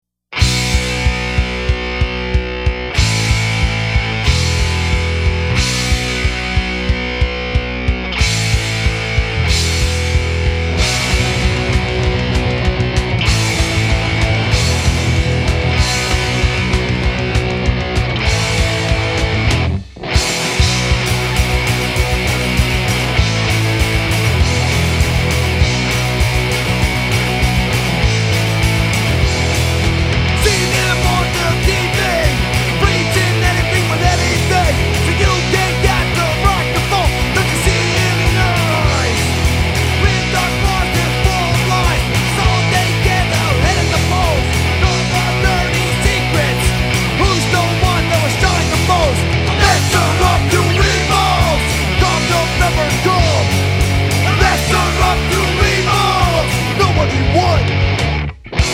unreleased skin and street punk pummel from